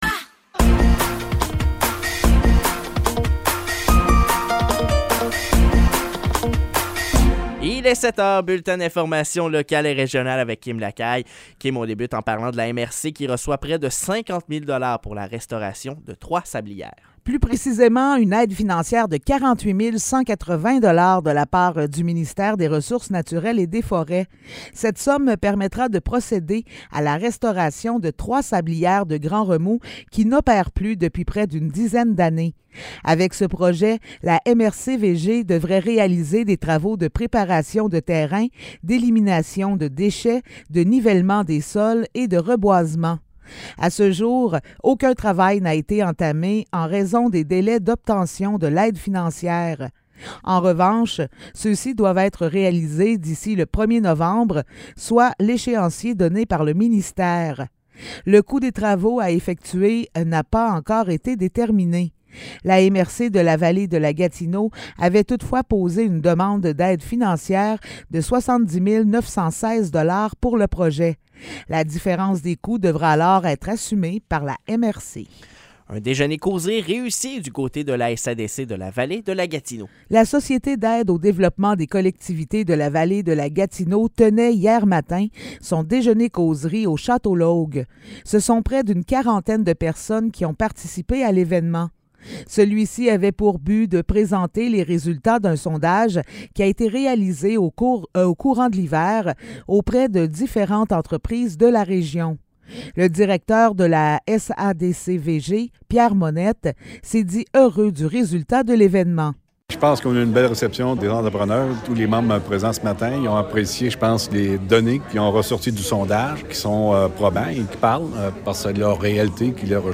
Nouvelles locales - 18 mai 2023 - 7 h